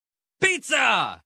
The “trim” is a high-energy audio clip from meme sound commonly used in memes, TikToks, and YouTube shorts to create a comedic or chaotic vibe. Featuring a punchy beat with iconic "trim", it's a staple in modern meme sound sound and meme culture.